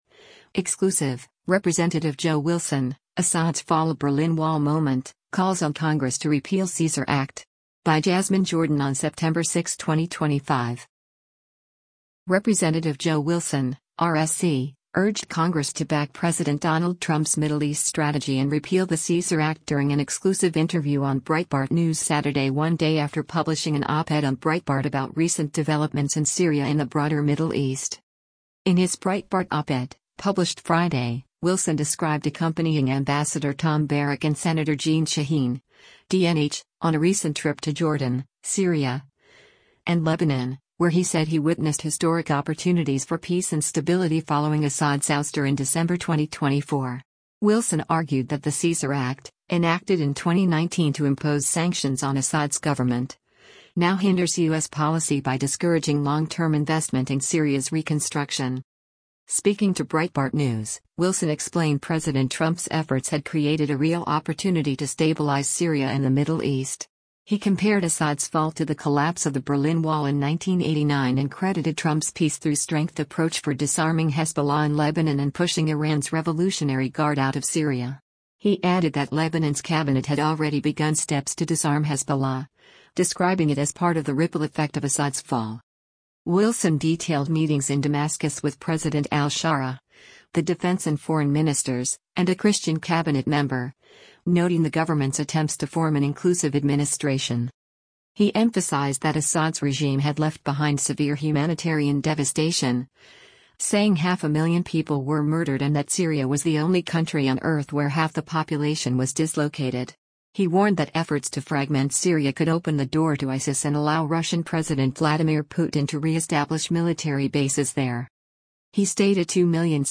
Rep. Joe Wilson (R-SC) urged Congress to back President Donald Trump’s Middle East strategy and repeal the Caesar Act during an exclusive interview on Breitbart News Saturday one day after publishing an op-ed on Breitbart about recent developments in Syria and the broader Middle East.